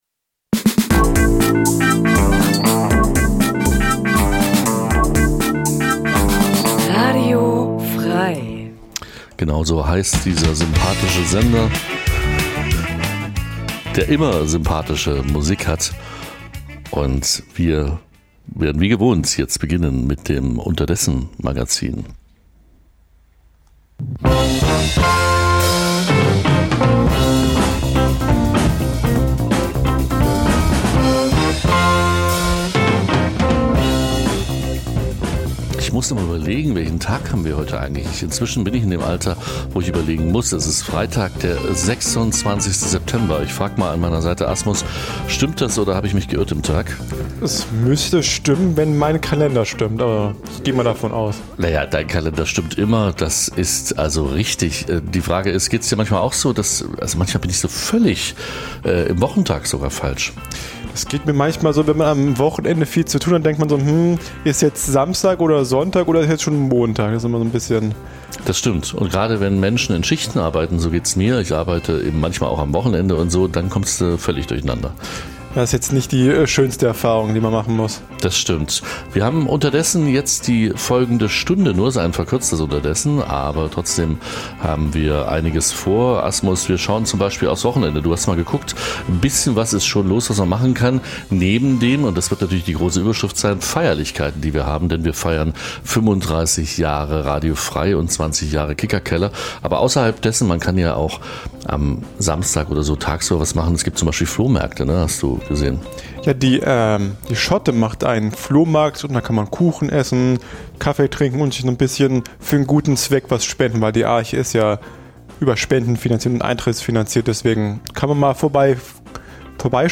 Das tagesaktuelle Livemagazin sendet ab 2024 montags bis freitags 9-11 Uhr. Jeden Tag von anderen Moderator:innen und thematisch abwechslungsreich best�ckt.